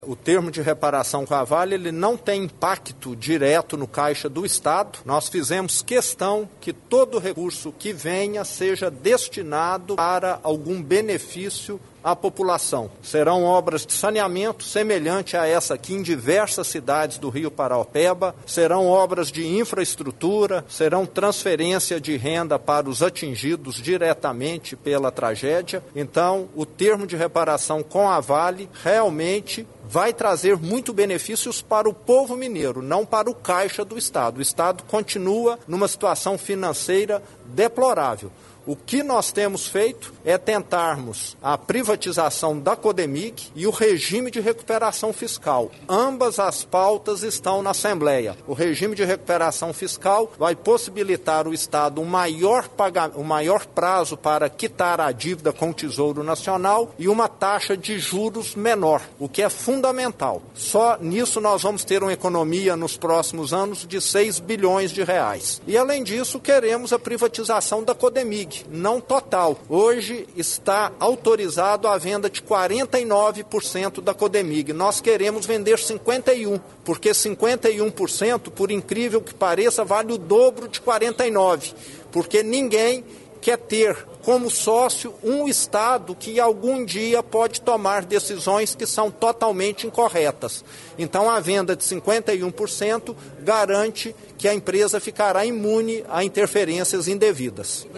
Governador Romeu Zema em coletiva de imprensa na cidade de Ubá – MG